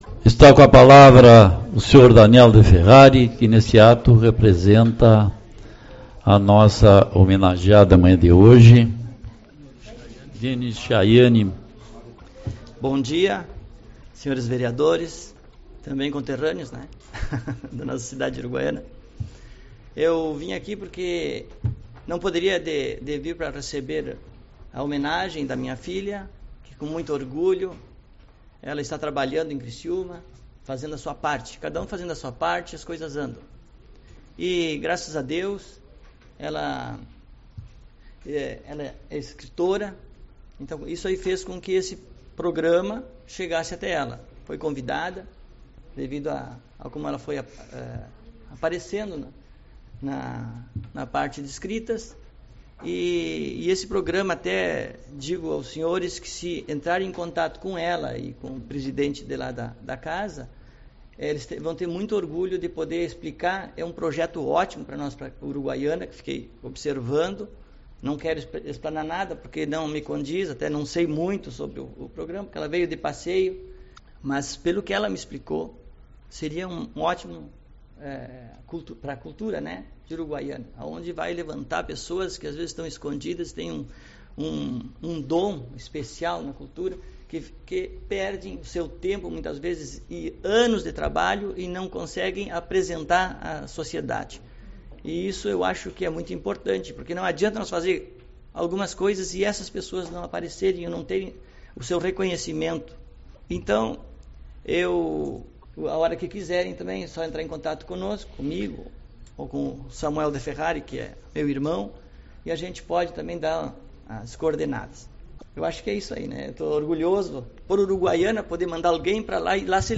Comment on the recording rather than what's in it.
08/03 - Reunião Ordinária